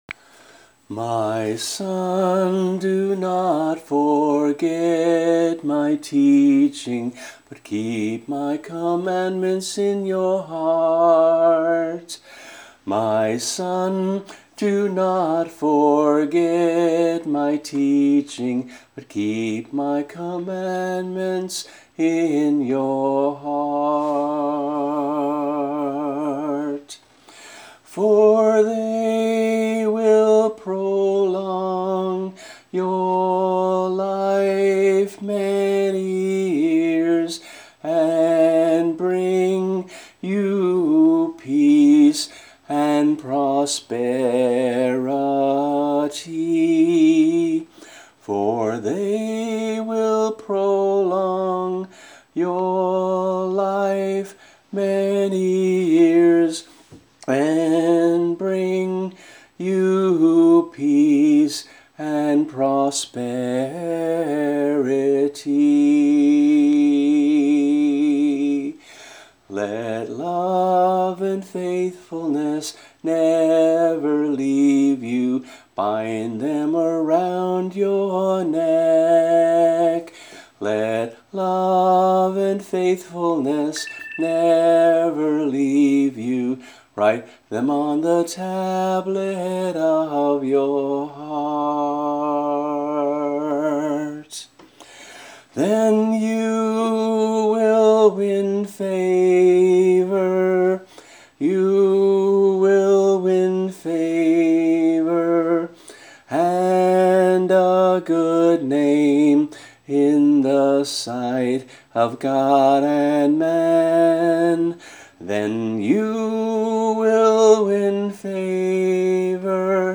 MP3 - voice only